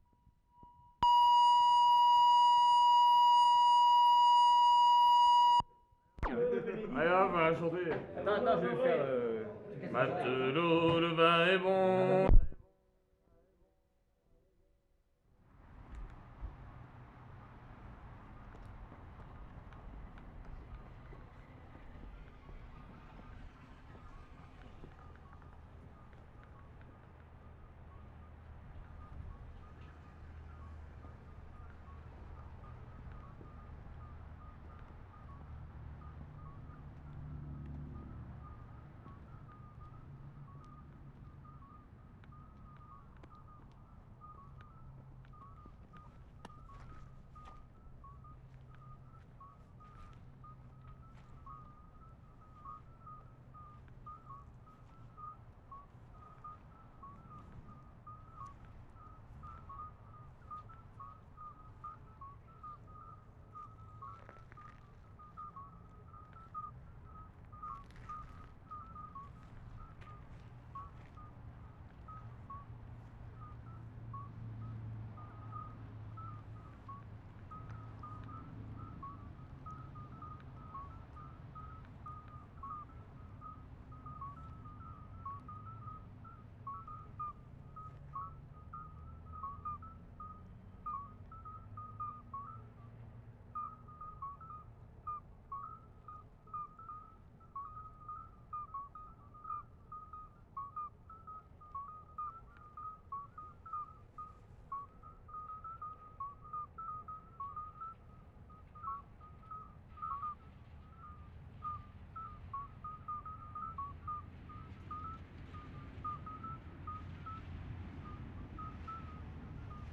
Rennes / Montreuil, France April 18/75
FROGS in pond at rear of hotel, (Au Reposée), near Rennes.
They sound more like a musical instrument of a Gamelan than frogs. Very exotic beautiful sound!